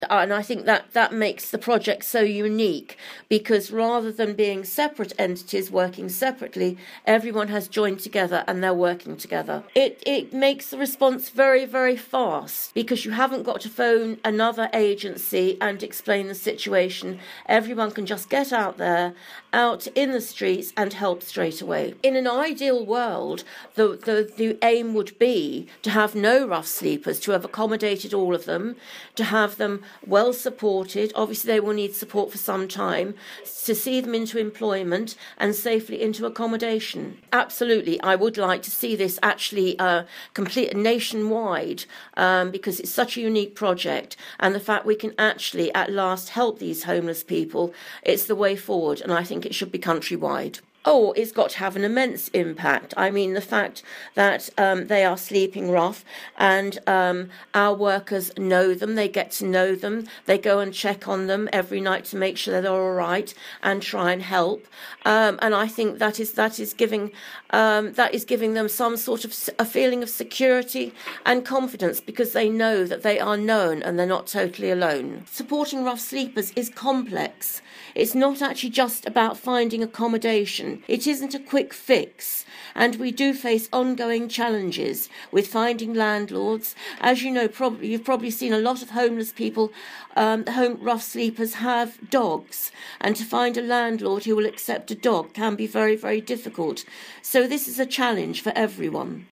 LISTEN: Lesley Game, Cabinet member for Housing at Thanet District Council says they've launched a unique project to support rough sleepers - 22/10/18